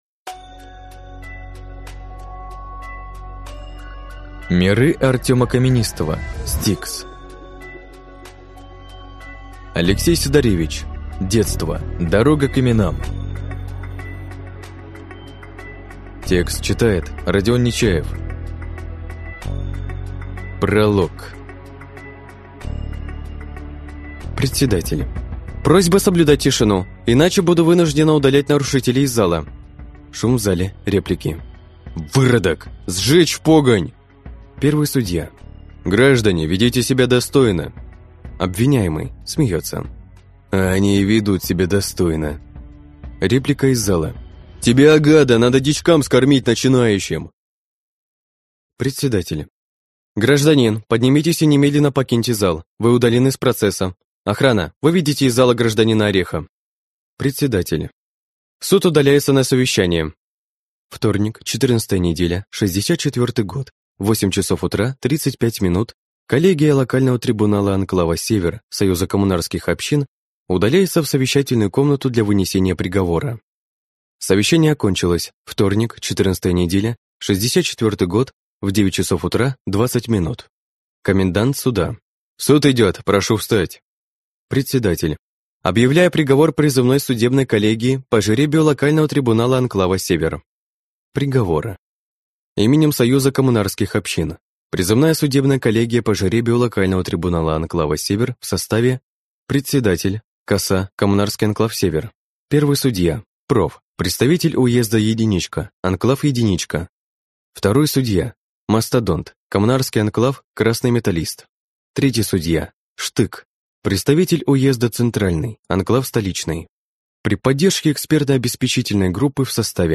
Аудиокнига S-T-I-K-S. Детство: дорога к именам | Библиотека аудиокниг